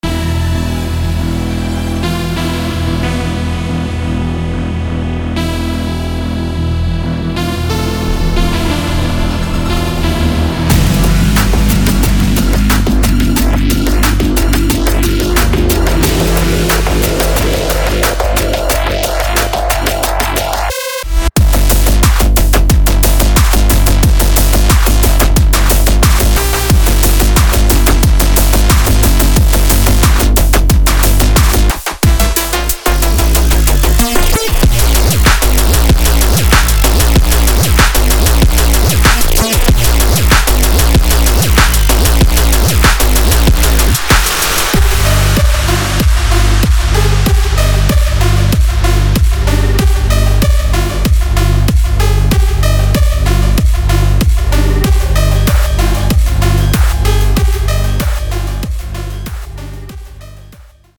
Midtempo